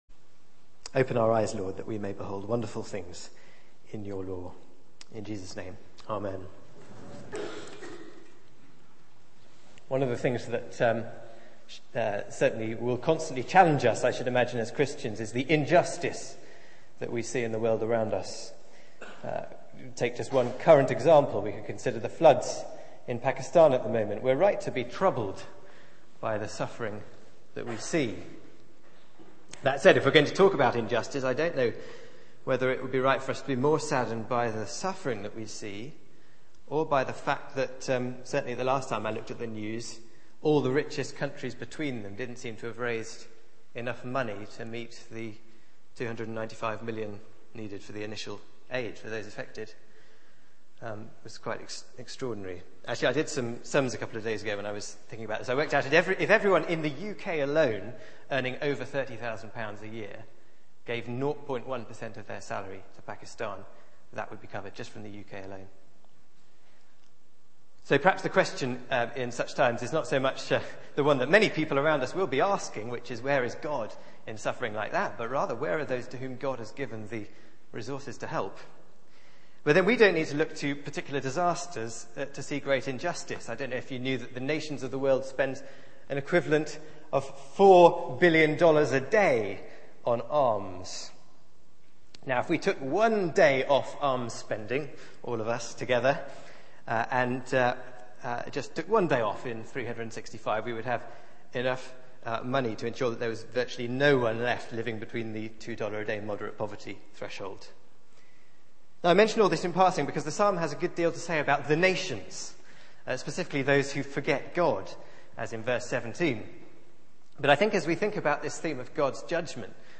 Media for 6:30pm Service on Sun 22nd Aug 2010 18:30 Speaker
Series: Summer songs Theme: The God who judges Sermon